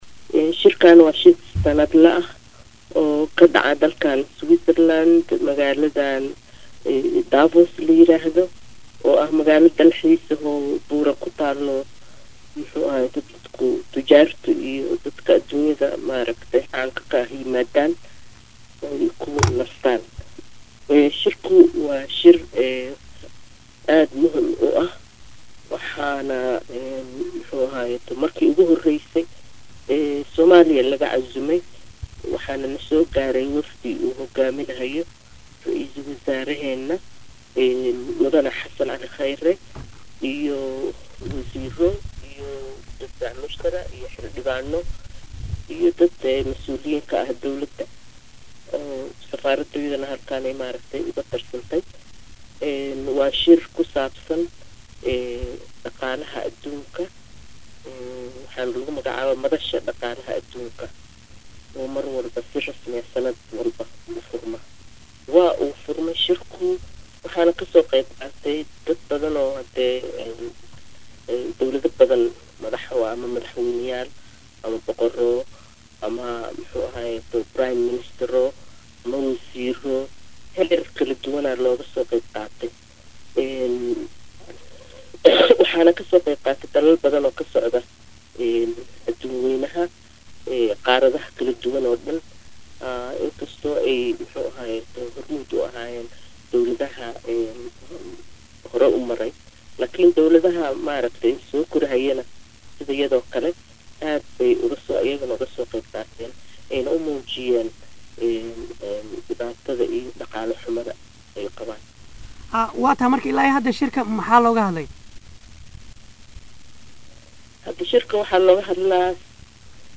Safiirka Soomaaliya u fadhiya Dalka Swezerland Faadumo C/llaahi Insaani oo la hadashay Radio muqdisho Codka Jamhuuriyadda Soomaaliya ayaa sheegtay in Maanta